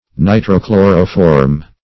Nitro-chloroform \Ni`tro-chlo"ro*form\, n. [Nitro- +